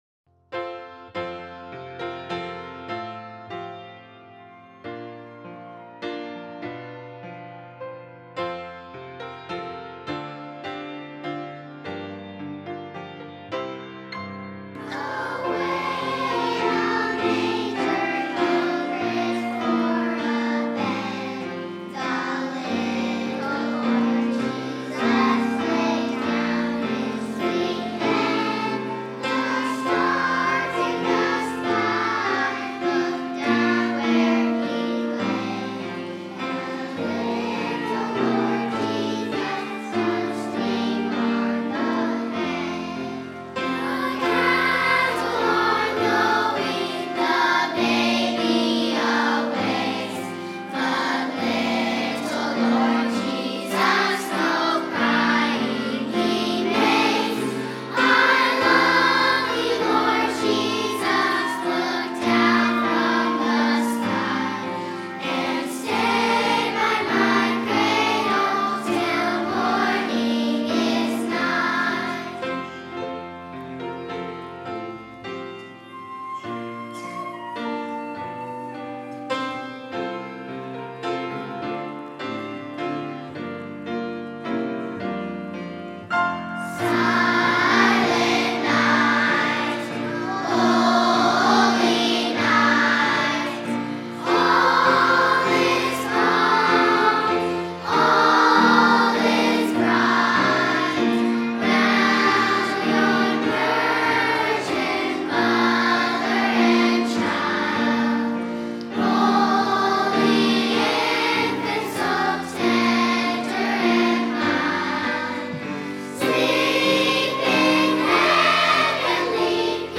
by VBC Children's Choir | Verity Baptist Church
Kids-Choir_1.mp3